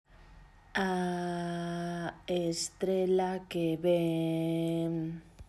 - as mesmas frases, farémolas con distintos ritmos sen cantar; un exemplo será o que segue, pero teredes que inventar un diferente e farémolo xuntas/os: